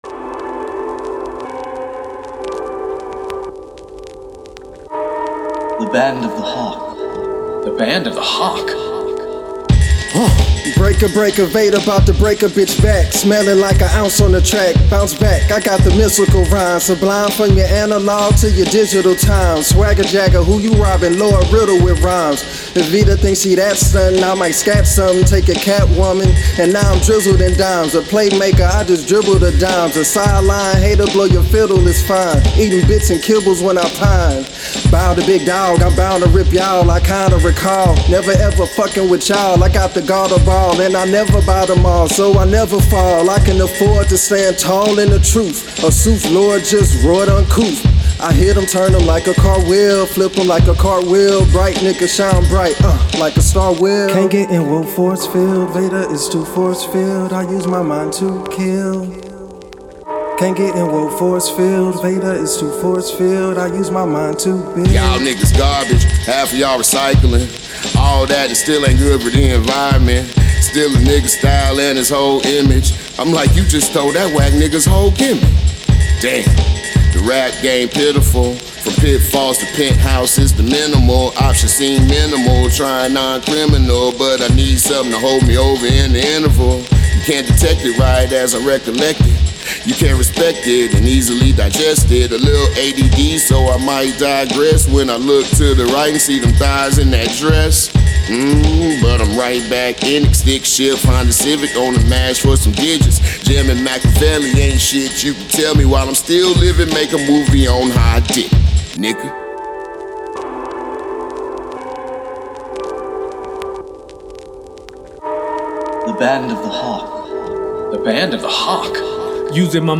Hiphop
all looped menace and blunted minutiae